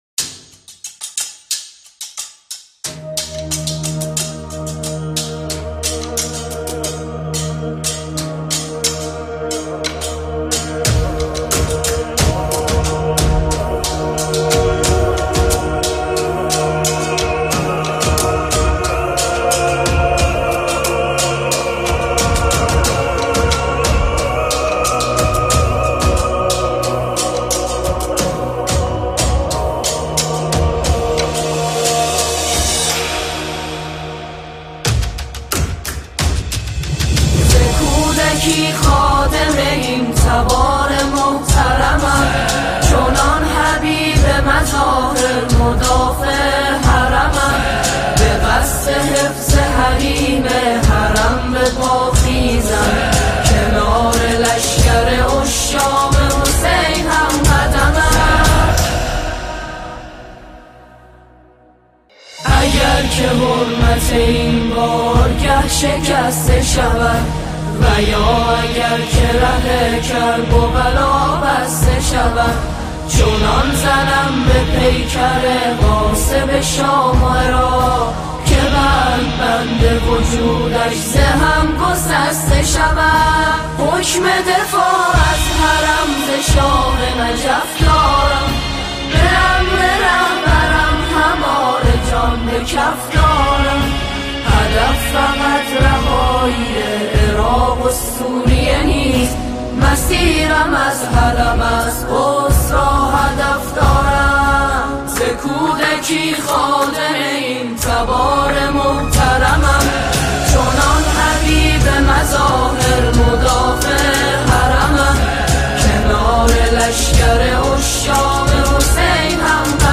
ولادت حضرت زینب (س)